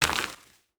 Dirt footsteps 12.wav